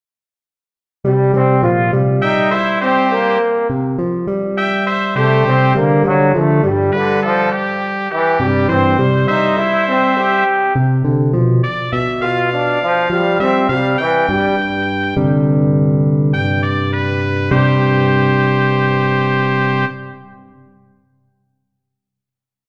The piece is written for standard clefs and in standard 4/4 time signature, but for the card I used the first letter of the genre and for the time signature I visualized the results of a game I played with my girls.
The classical genre, with most of the CDs, has all of the chords. The CDs of brass music have little fanfares and the jazz CDs are represented with a jazzy bass line.
Classical and Brass sections together